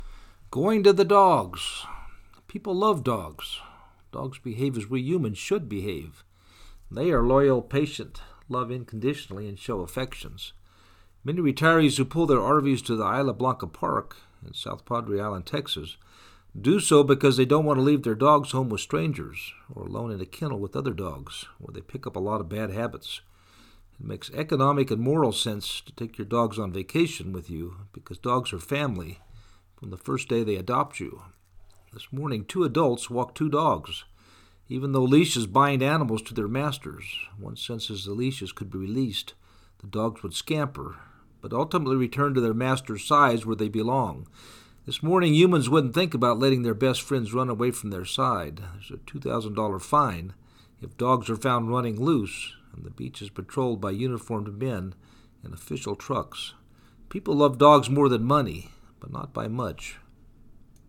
Going to the Dogs South Padre Island beach